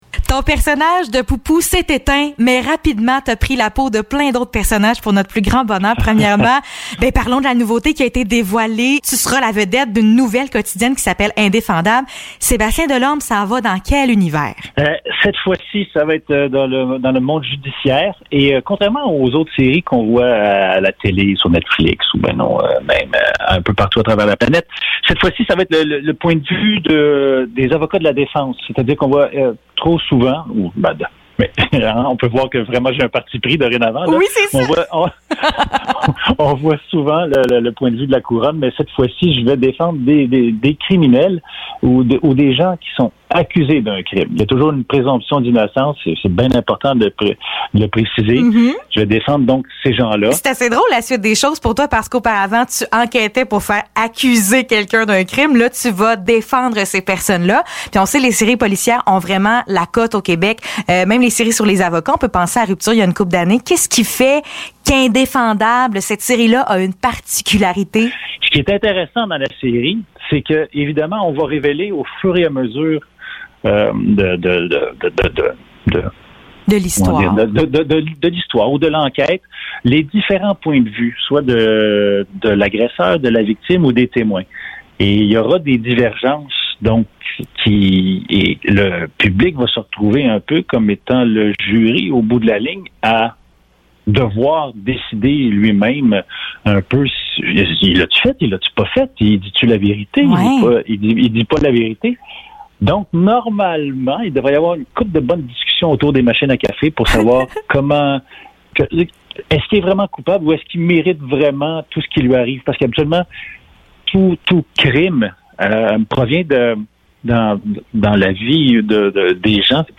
Entrevue avec le comédien Sébastien Delorme (17 janvier 2022)